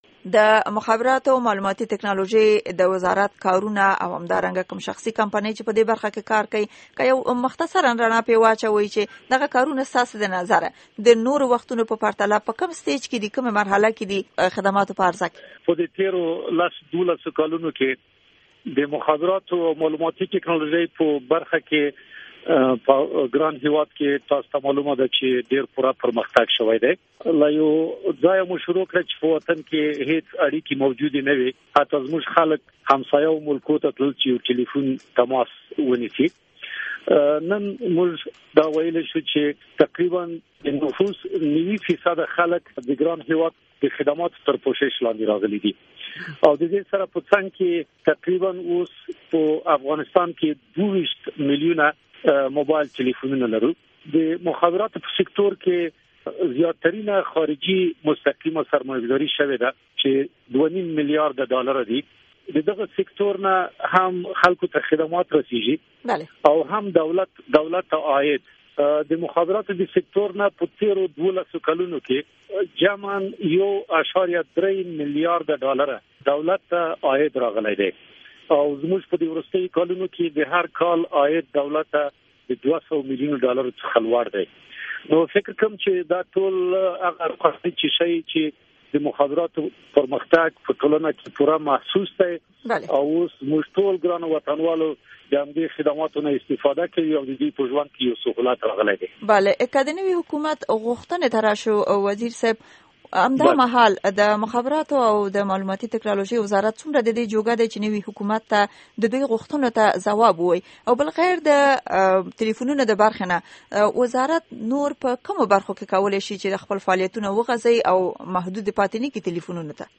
د امیرزي سنګین سره مرکه